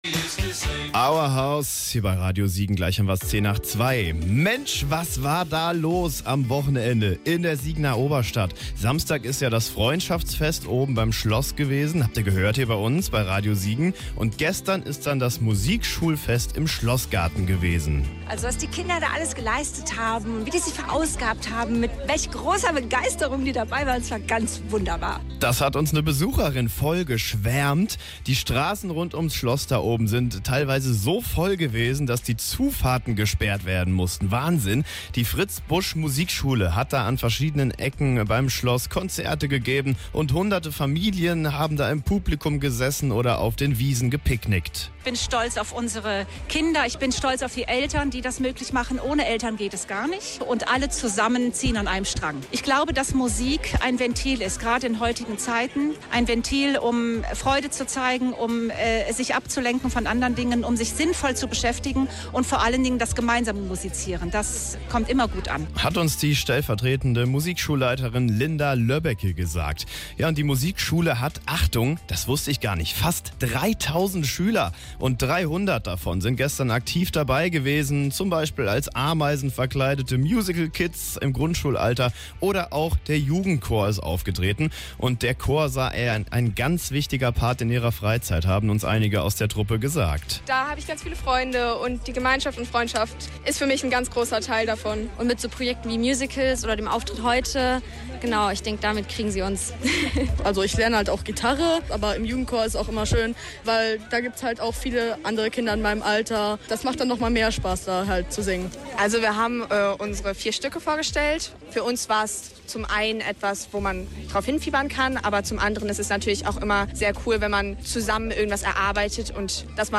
Musikschulfest am Schloss
Full House im Schlossgarten: Fast 3000 Schüler hat die Fritz-Busch-Musikschule aus Siegen. Mehrere hundert davon performten am Sonntag rund um's Schloss.